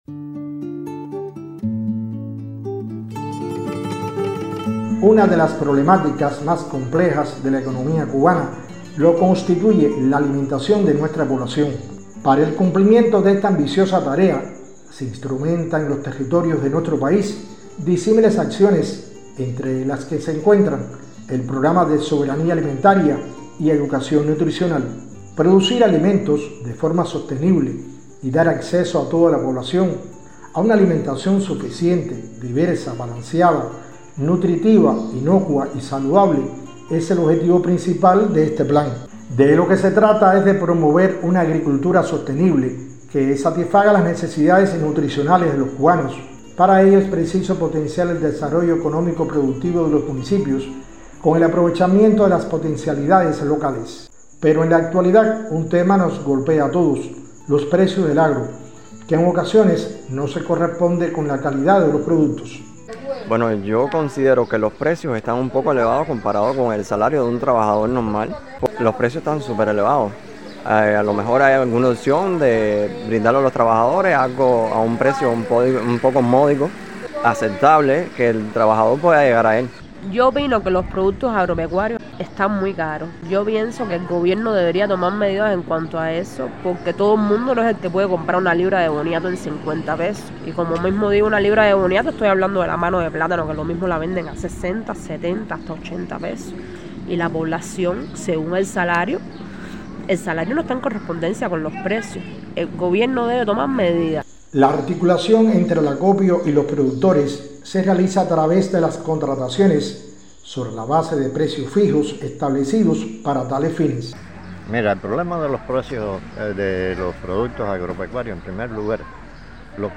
reporte
desde Los Arabos.